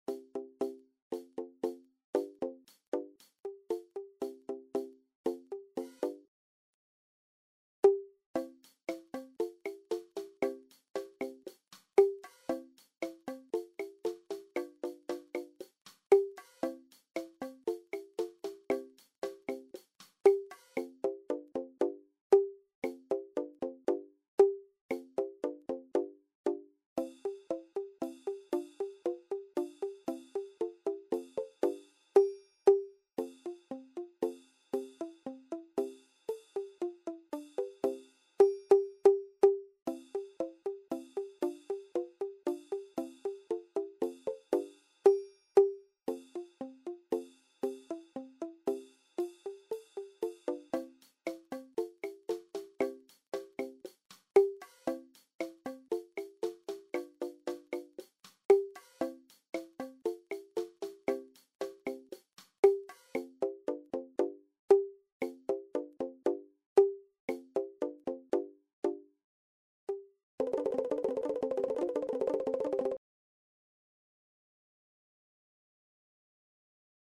Bladmuziek voor junior percussie ensemble, entertainment.